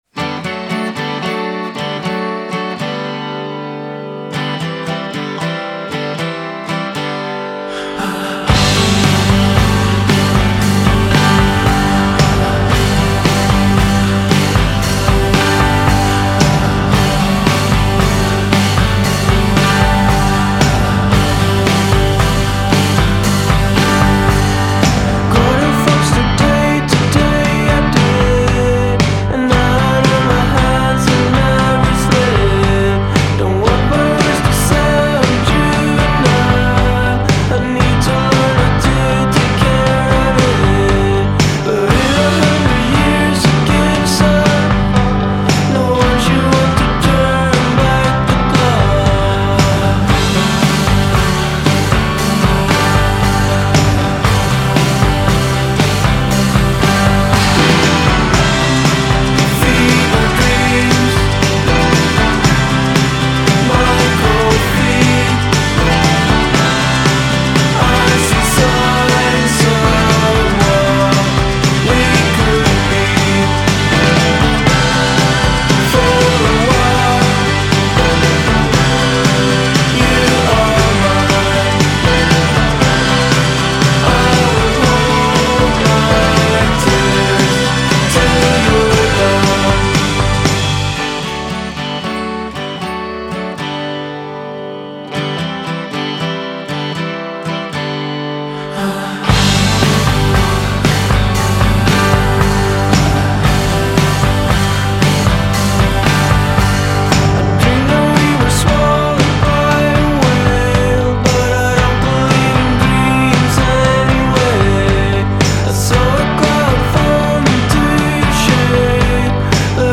Swedish duo
complex yet easy-to-listen-to pop music